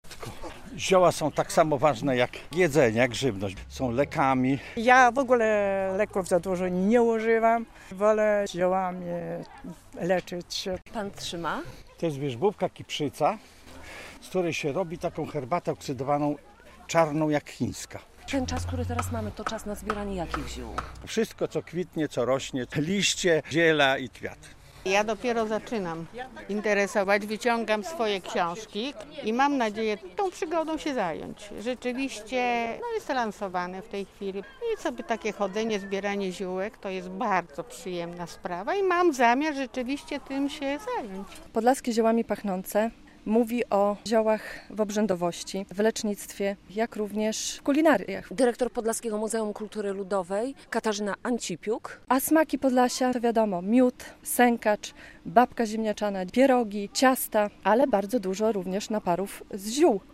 Warsztaty, pokazy, występy artystyczne i wystawy. Między innymi takie atrakcje czekają w niedzielę (09.07) w Podlaskim Muzeum Kultury Ludowej w Wasilkowie. O 11:00 rozpoczął się festyn "Podlaskie ziołami pachnące - Smaki Podlasia".